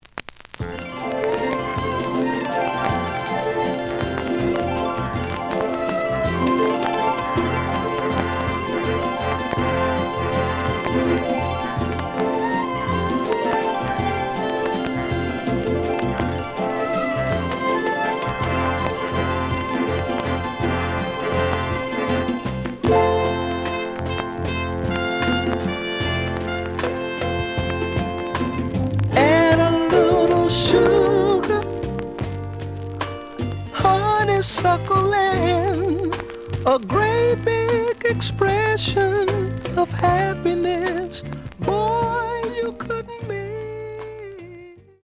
The audio sample is recorded from the actual item.
●Genre: Funk, 70's Funk
Some click noise on B side due to scratches.